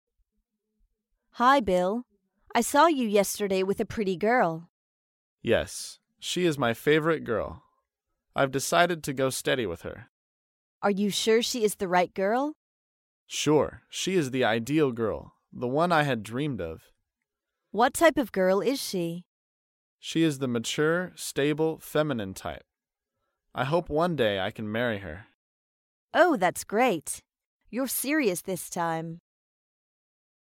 在线英语听力室高频英语口语对话 第157期:追求女孩的听力文件下载,《高频英语口语对话》栏目包含了日常生活中经常使用的英语情景对话，是学习英语口语，能够帮助英语爱好者在听英语对话的过程中，积累英语口语习语知识，提高英语听说水平，并通过栏目中的中英文字幕和音频MP3文件，提高英语语感。